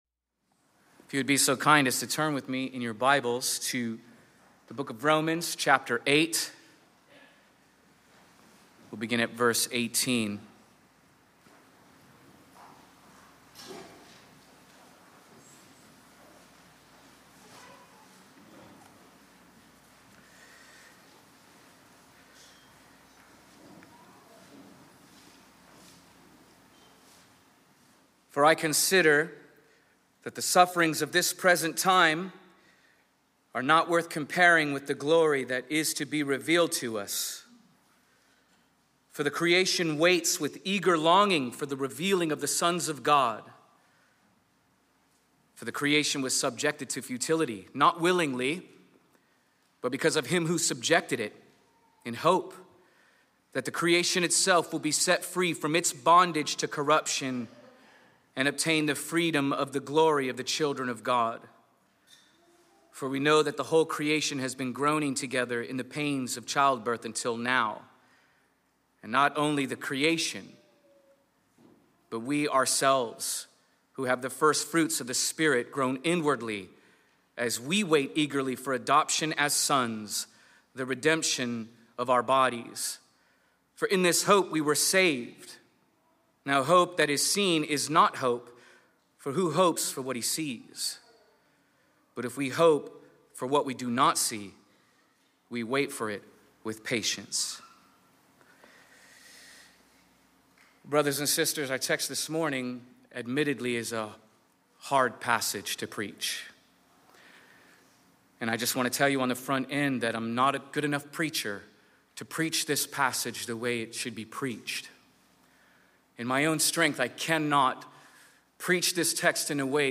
This sermon is titled “The Hallway of Hope”, which points us to the hope we have beyond our suffering. Because God sent His Son for the sole purpose of suffering for us, he will deliver his people and his creation from it’s futility and suffering.